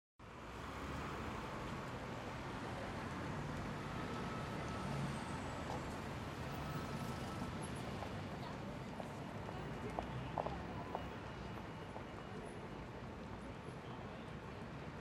Background Sound Effects, Transportation Sound Effects
traffic_06-1-sample.mp3